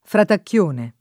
fratacchione [ fratakk L1 ne ] s. m.